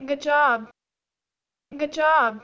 sample stimulus pair